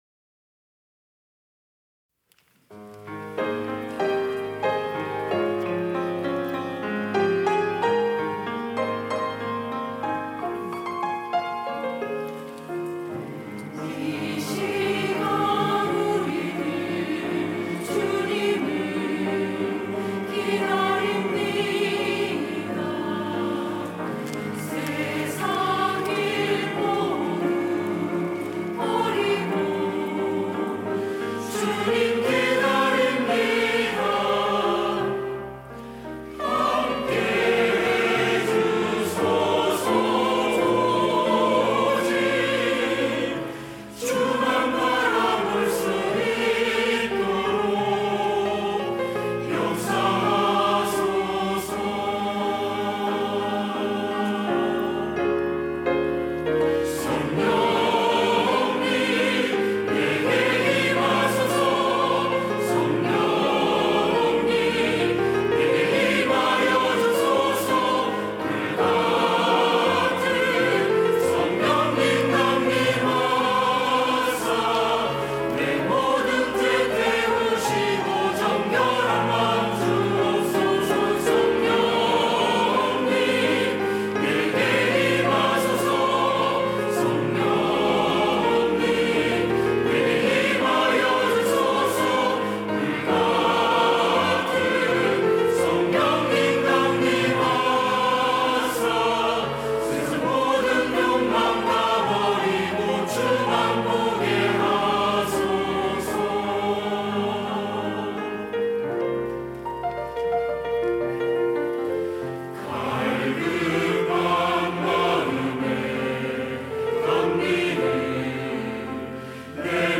시온(주일1부) - 성령님!
찬양대